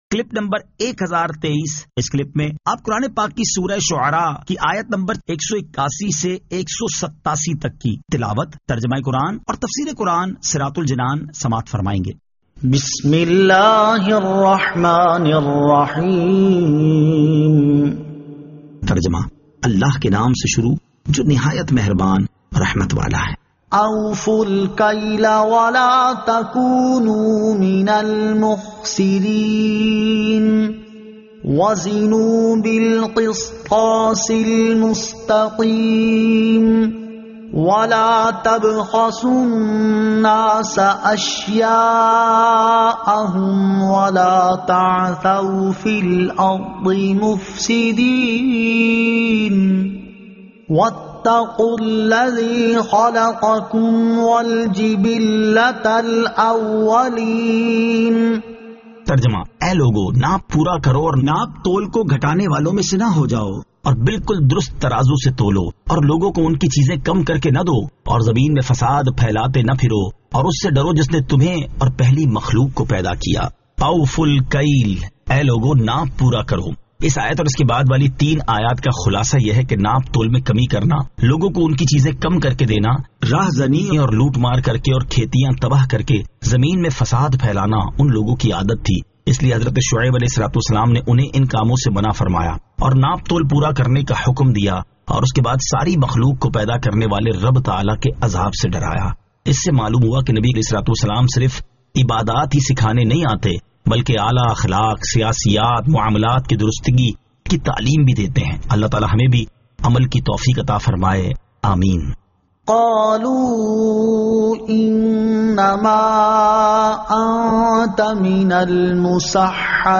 Surah Ash-Shu'ara 181 To 187 Tilawat , Tarjama , Tafseer
2022 MP3 MP4 MP4 Share سُوْرَۃُ الشُّعَرَاءِ آیت 181 تا 187 تلاوت ، ترجمہ ، تفسیر ۔